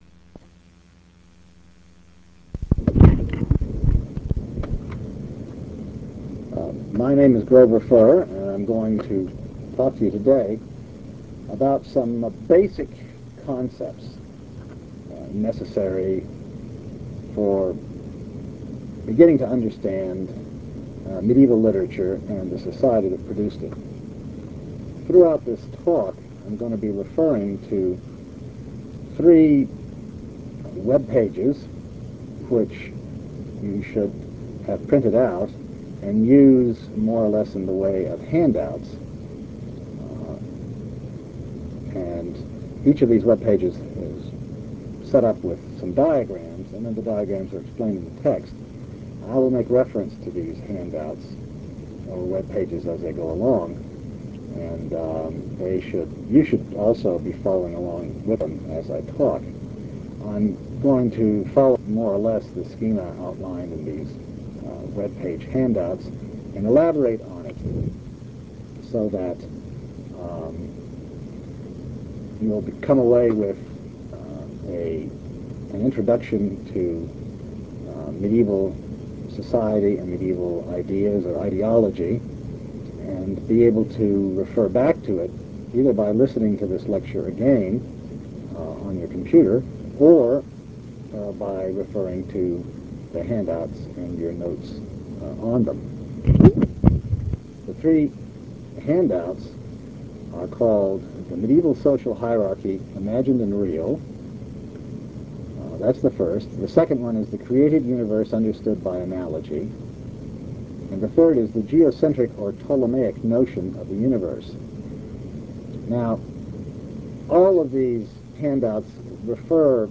While listening to, and making notes on, this lecture, make sure you are looking at the handouts, or Web Pages that accompany this lecture (and the next two lectures as well): The Social Hierarchy; The Universal Hierarchy; and the Ptolemaic Theory of the Universe.